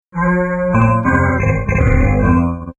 stick 1 2 sec. stereo 68k
stick1.mp3